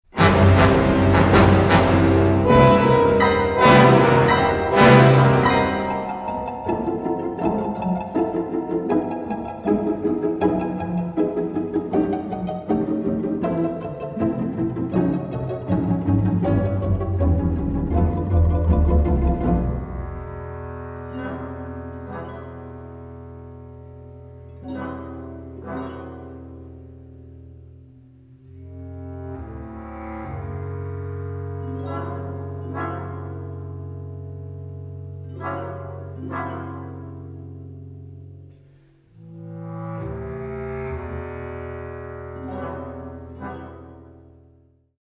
Recorded on the OSE orchestra stage in September 2018